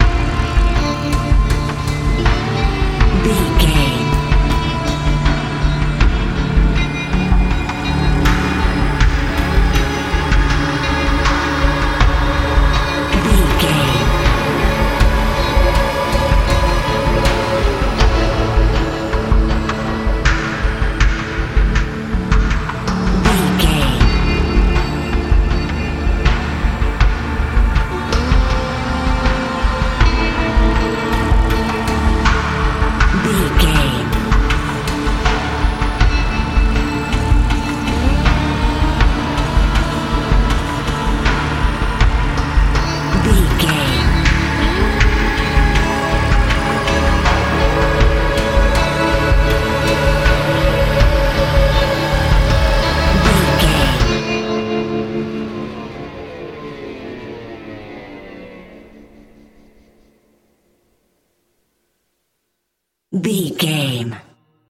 Thriller
Aeolian/Minor
Slow
drum machine
synthesiser
electric piano
electric guitar